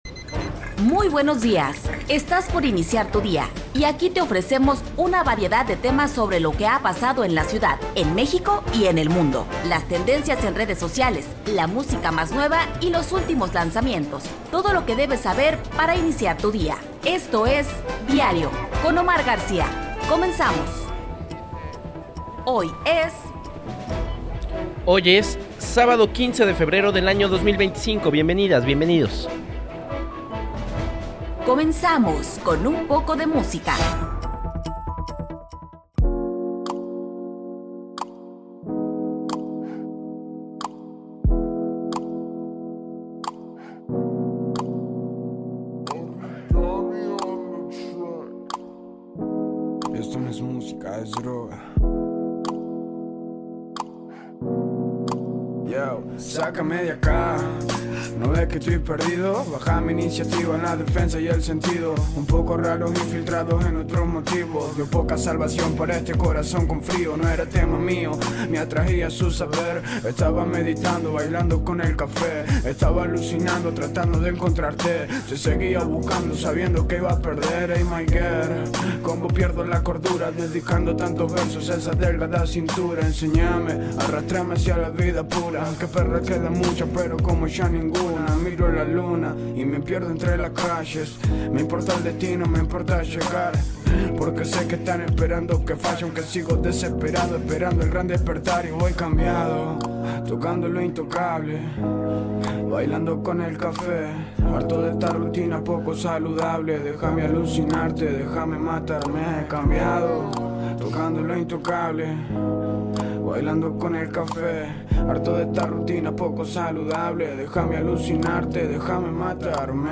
Hoy en Diario, Revista Informativa de Radio Universidad de Guadalajara